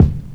break_kick_3.wav